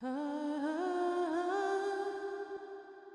嗯啊啊啊混响
描述：带混响的
标签： 150 bpm Dance Loops Vocal Loops 543.80 KB wav Key : Unknown
声道立体声